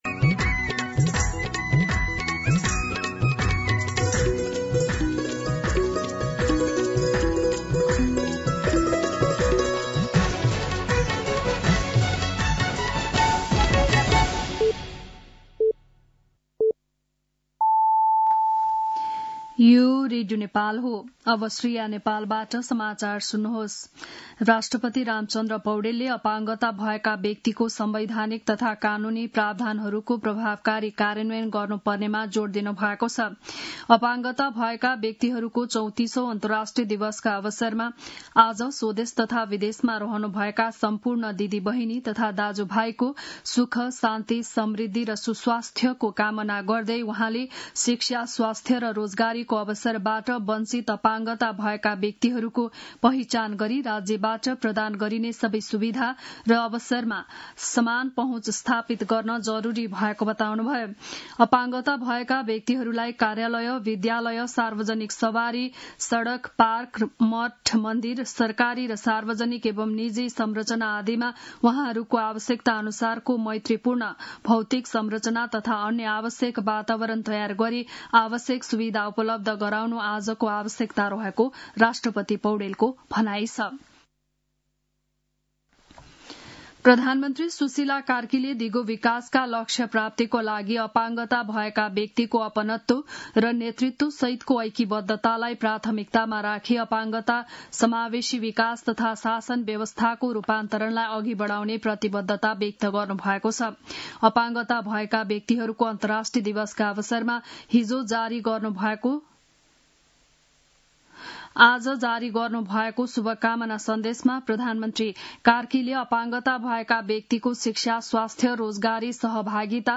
An online outlet of Nepal's national radio broadcaster
बिहान ११ बजेको नेपाली समाचार : १७ मंसिर , २०८२